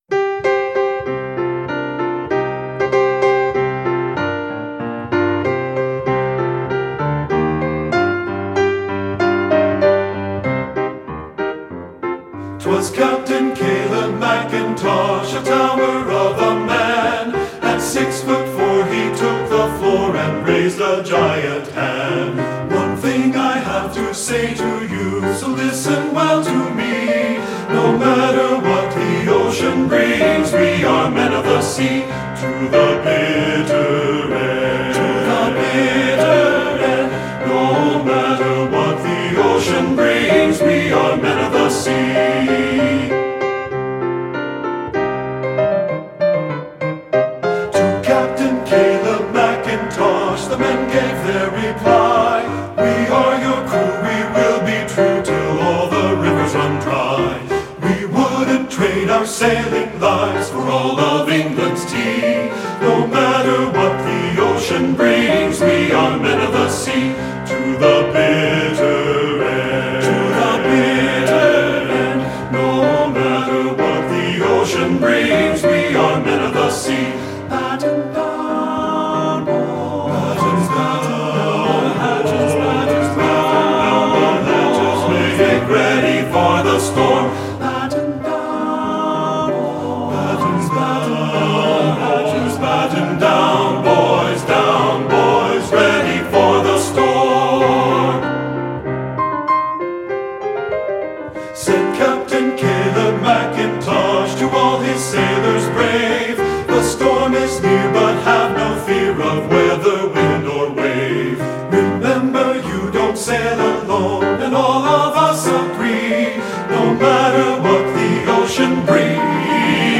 Voicing: TTB and Piano